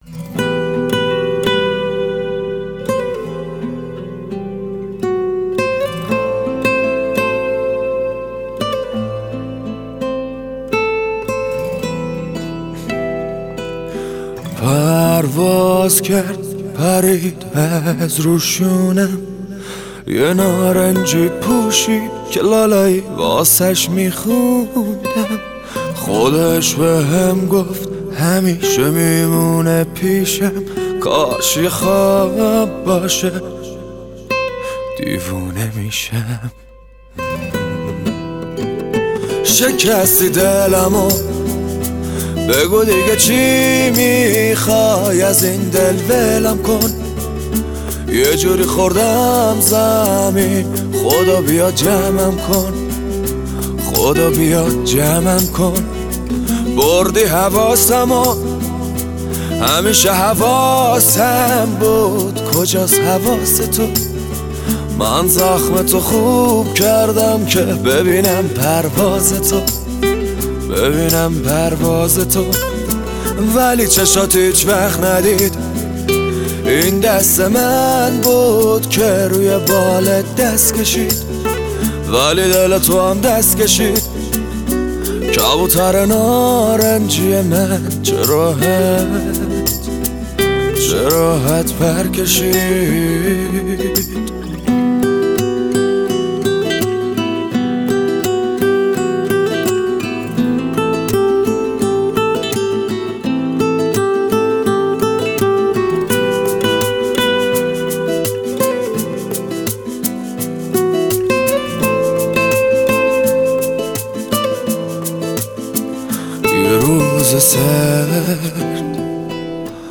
نسخه گیتار